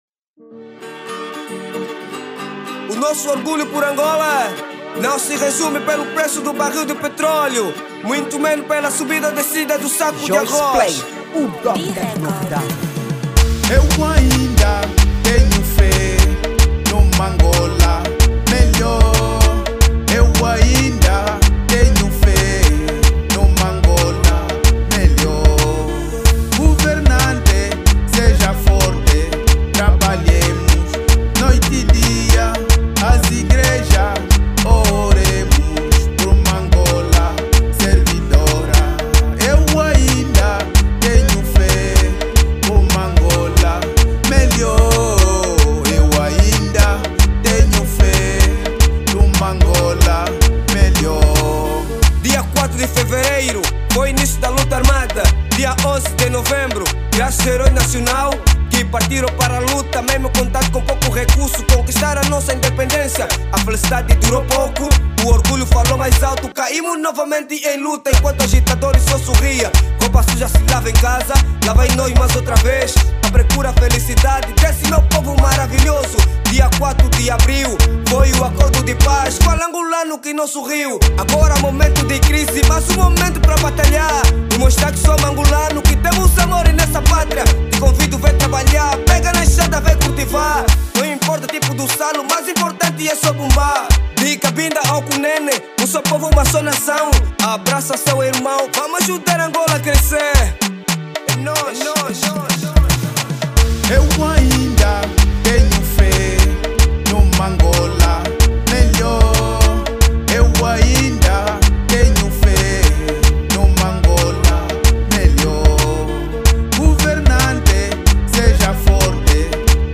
| Kuduro